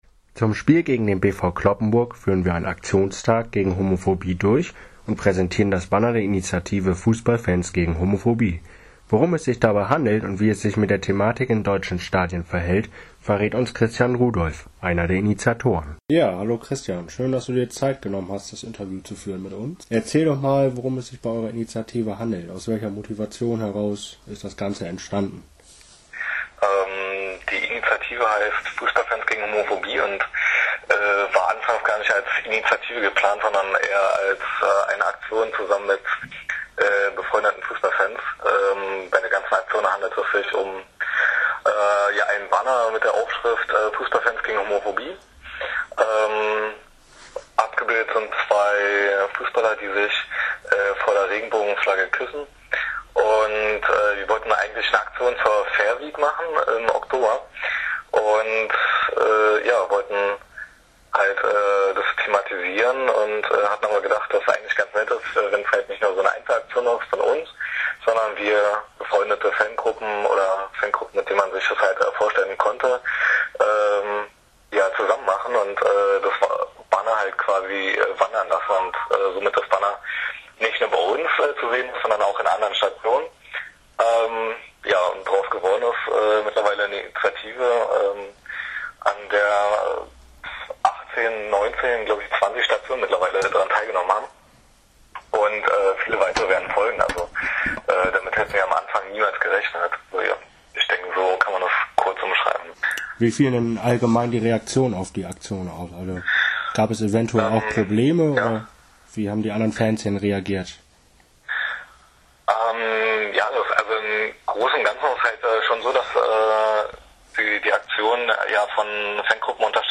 VfA Interview